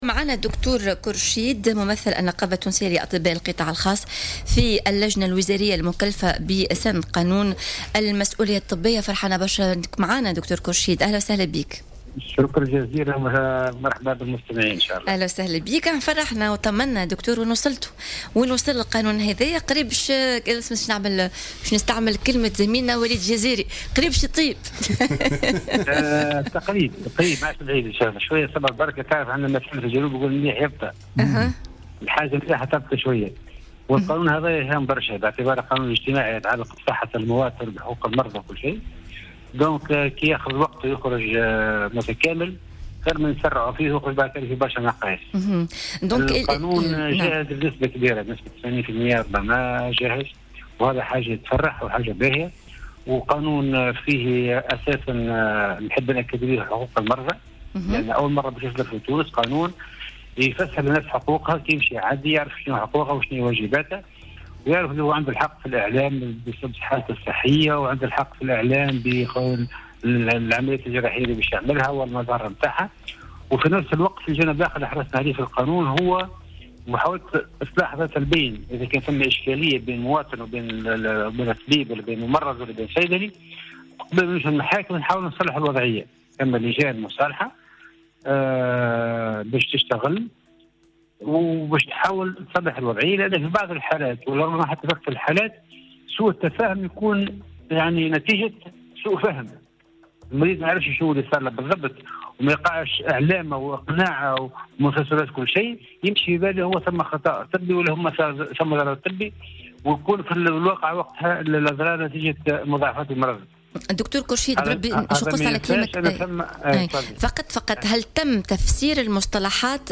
وأضاف خلال مداخلته في برنامج "S.O.S Avocat"، أن المشروع ينص على تقديم تعويضات مالية للمرضى الذين يتعرضون إلى حوادث طبية، سواء في المؤسسات الطبية العمومية أو الخاصة، ومن حق المريض رفض التعويض واللجوء إلى القضاء. ووفق مشروع القانون، فإن الطبيب يعاقب قانونيا إذا ثبت ارتكابه لخطأ طبي.